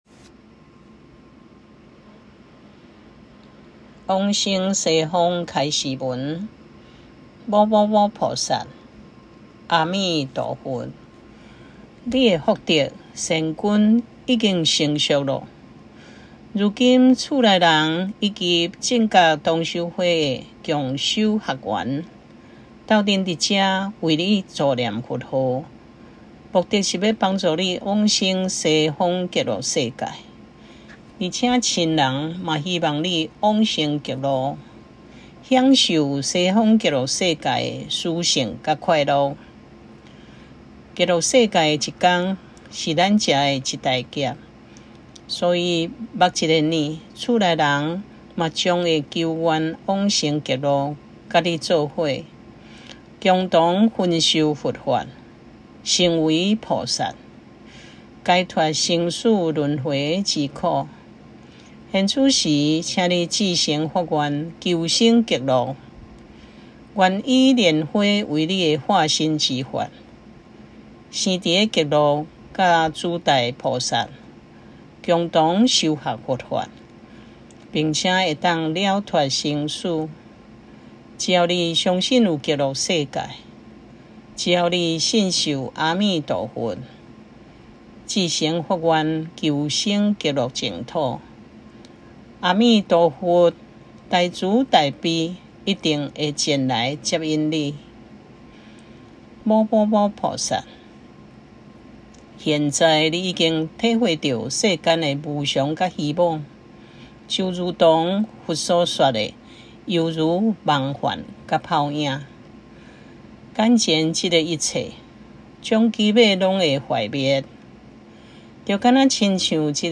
往生極樂淨土(彌陀)─梵唄教學音檔
2.往生極樂淨土助念開示文(台語)